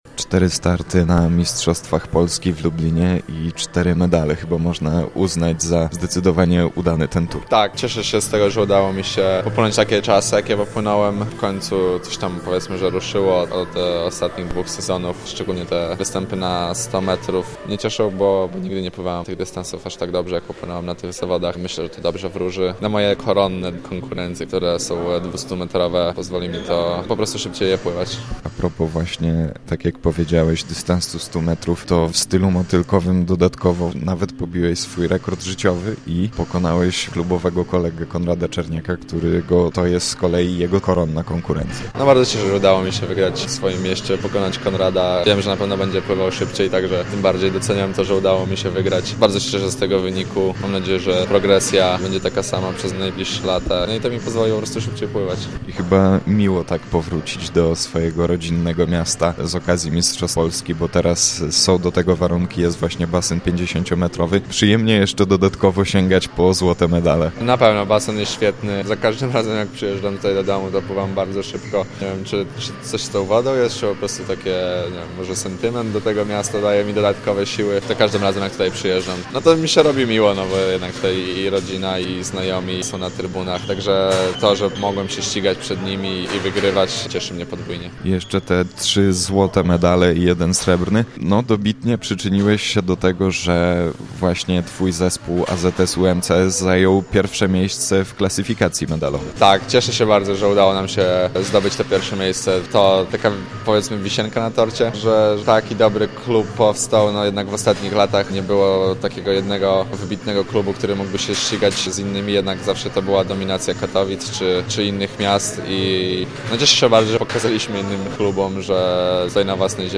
MINIWYWIAD-Jan-Świtkowski.mp3